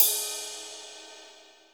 RIDE CS2  -S.WAV